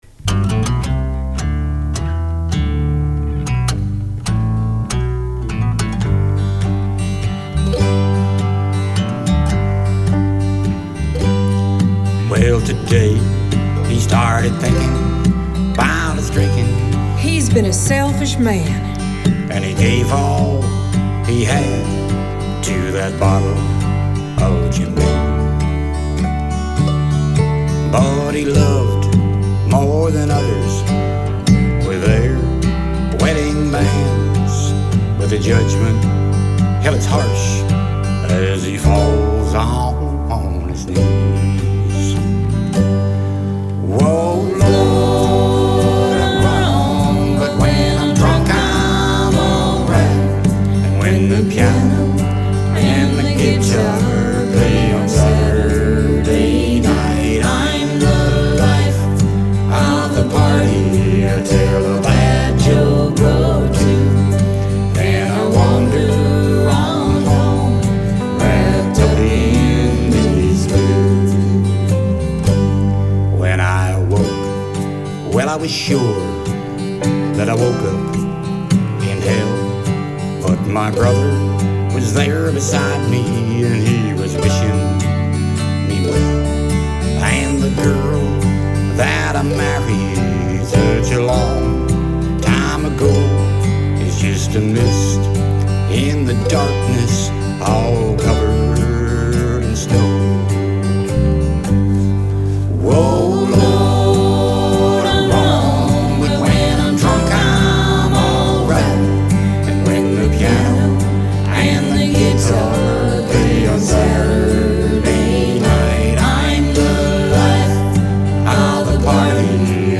One obvious problem is the dude's voice.